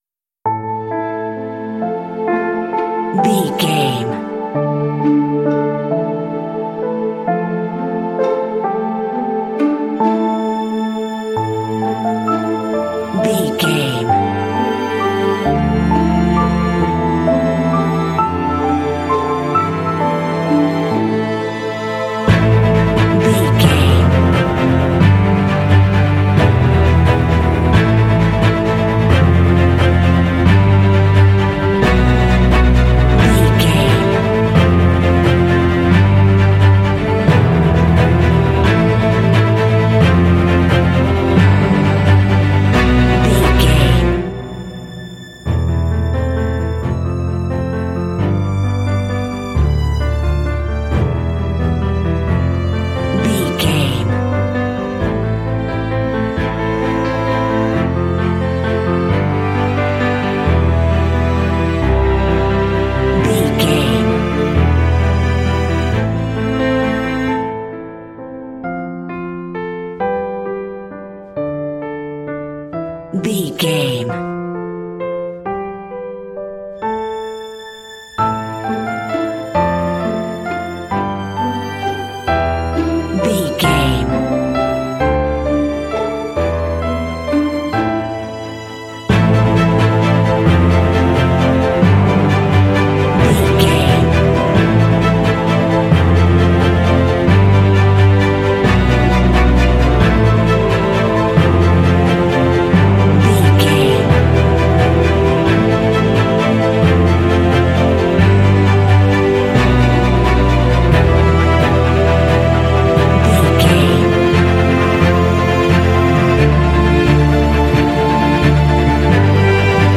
Aeolian/Minor
melancholy
smooth
piano
strings
orchestral
cinematic